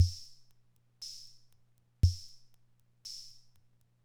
Index of /90_sSampleCDs/300 Drum Machines/Conn Min-O-Matic Rhythm/Conn Min-O-Matic Rhythm Ableton Project/Samples/Imported